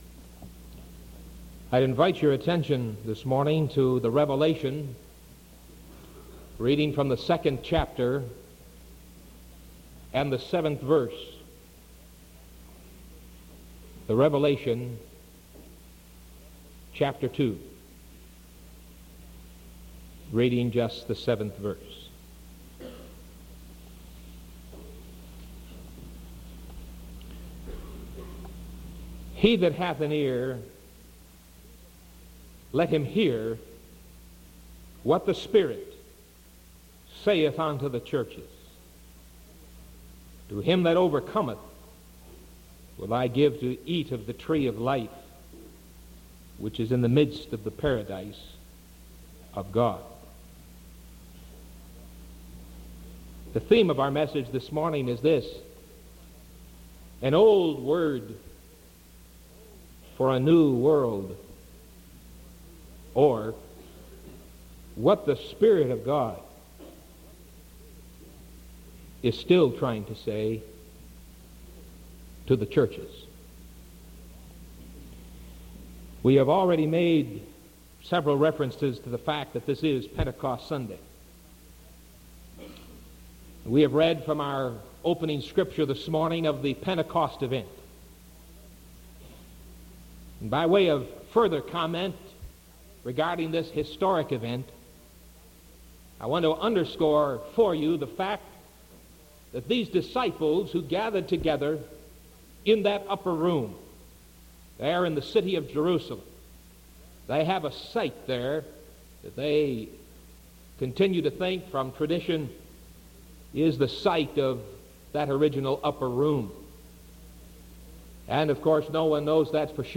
Sermon May 18th 1975 AM